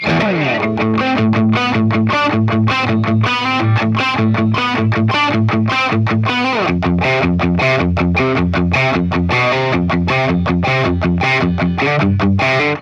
It's incredible sounding when pushed! Bright, edgy and alot of attack!
Metal Riff
RAW AUDIO CLIPS ONLY, NO POST-PROCESSING EFFECTS